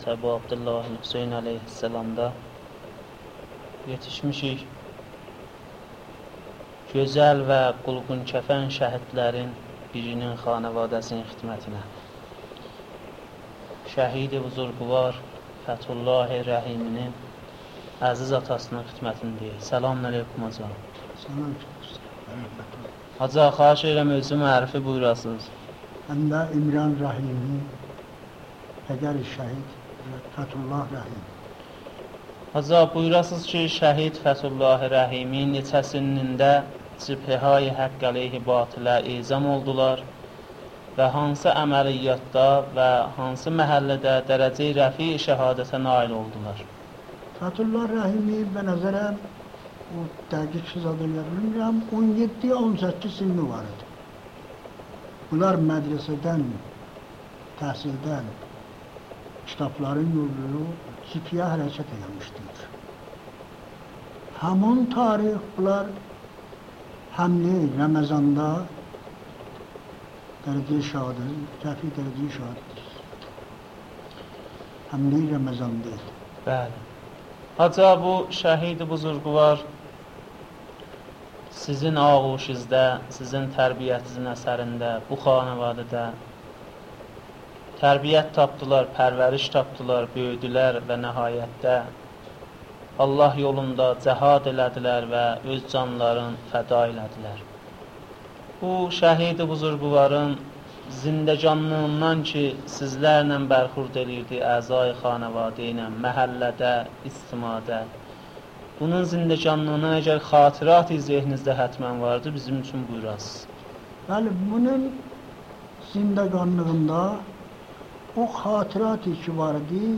صوت / مصاحبه با خانواده شهید